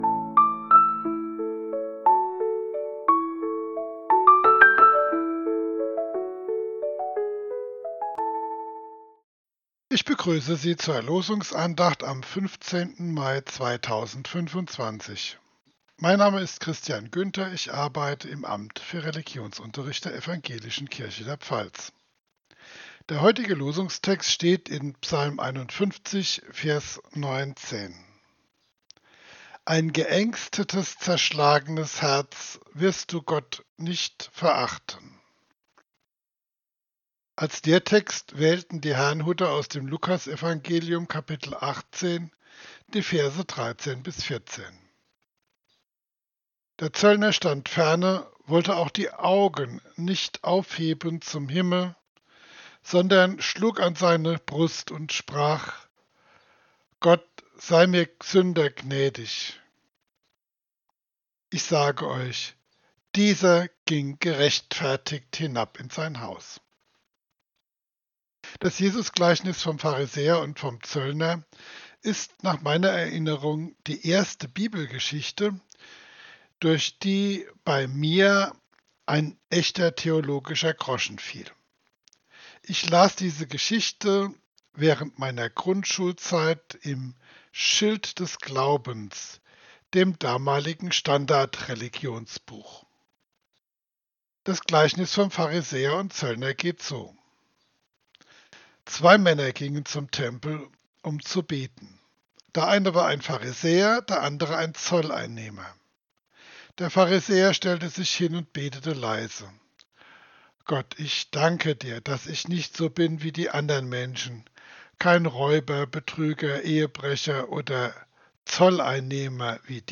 Losungsandacht für Donnerstag, 15.05.2025